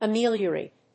音節a・me・lio・rate 発音記号・読み方
/əmíːljərèɪt(米国英語), ʌˈmi:ljɜ:ˌeɪt(英国英語)/
ameliorate.mp3